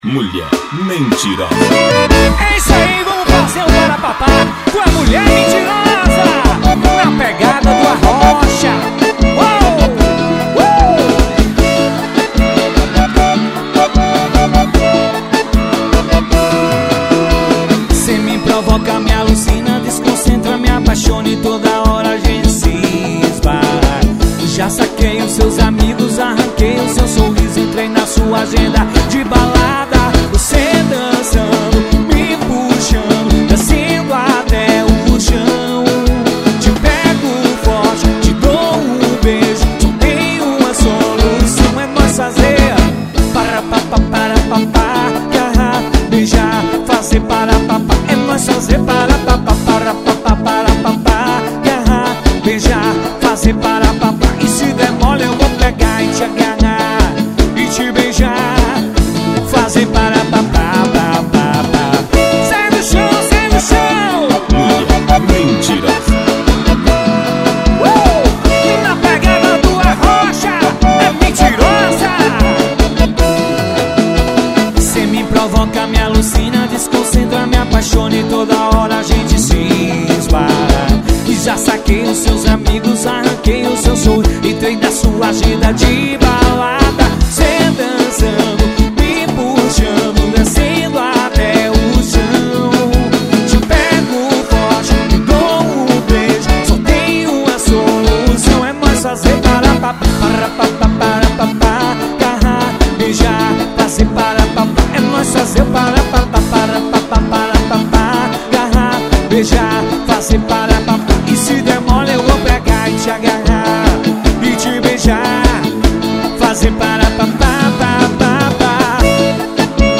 na pegada do arrocha